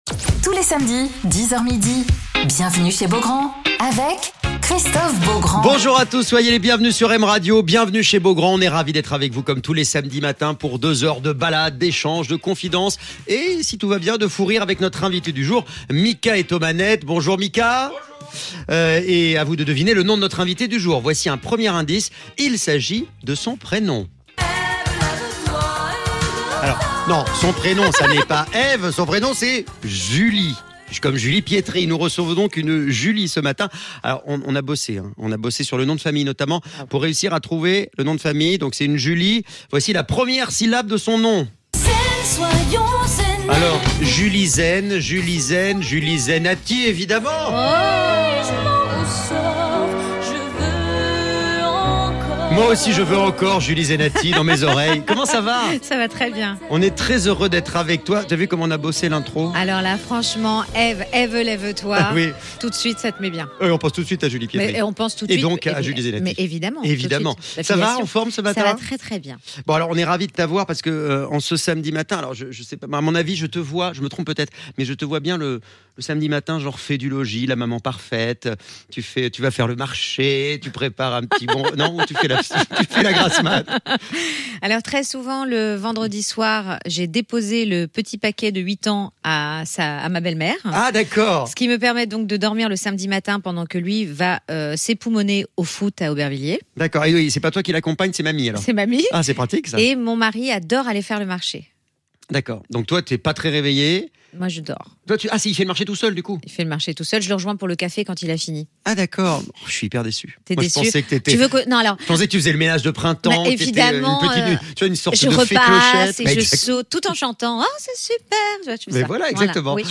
Alors que sa tournée dans les églises se poursuit partout en France, où elle présente son dernier album "Le Chemin", avant le point d'orgue le 17 septembre à l'église de la Madeleine à Paris, Julie Zenatti est l'invitée de Christophe Beaugrand sur M Radio !